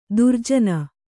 ♪ durjaa